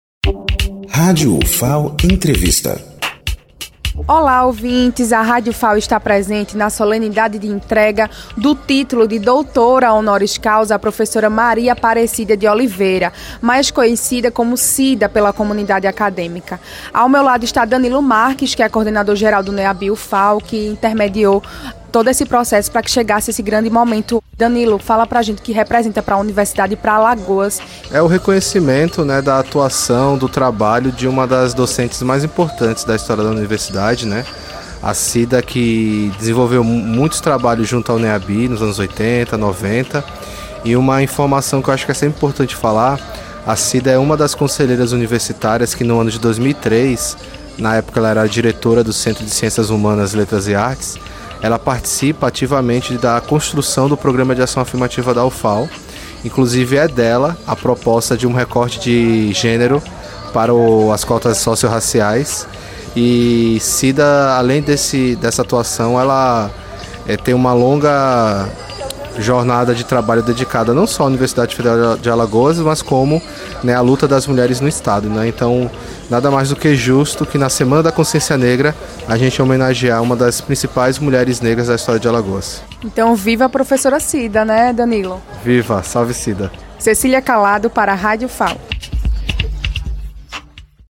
Cerimônia de concessão do título de Doutora Honoris Causa da Universidade Federal de Alagoas (Ufal)